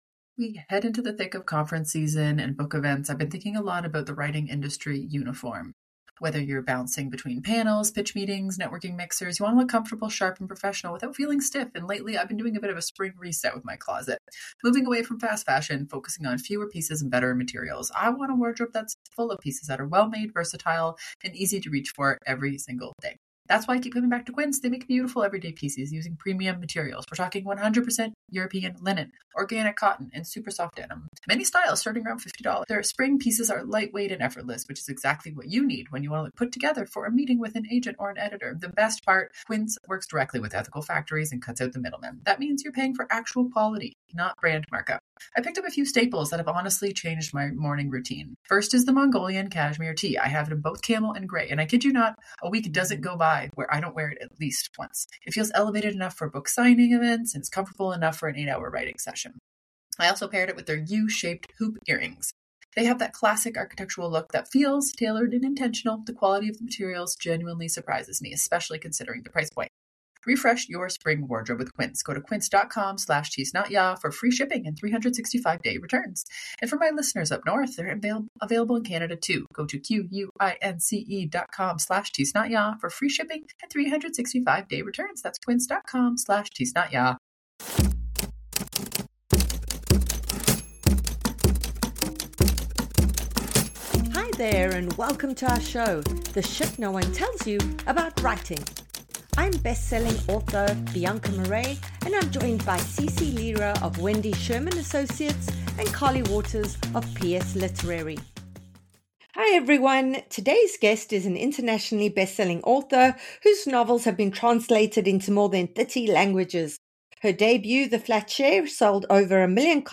A double author interview you don’t want to miss!